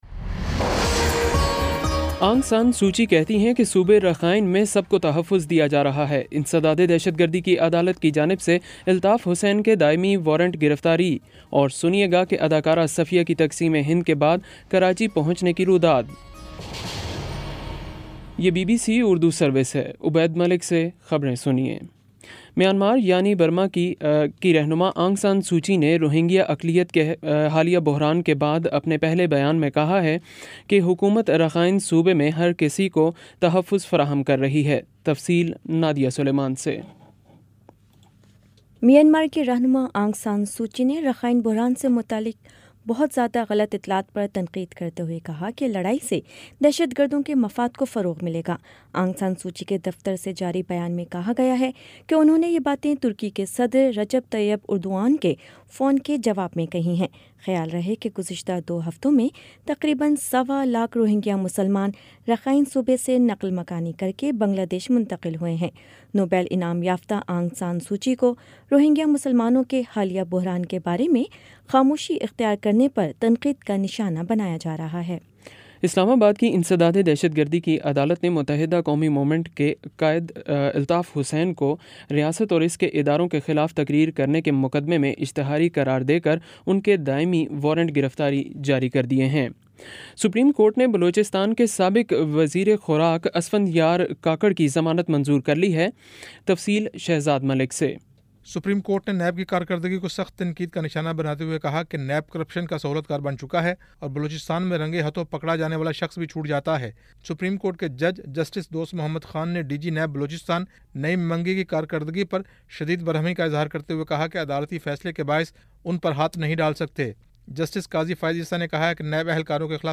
ستمبر 06 : شام پانچ بجے کا نیوز بُلیٹن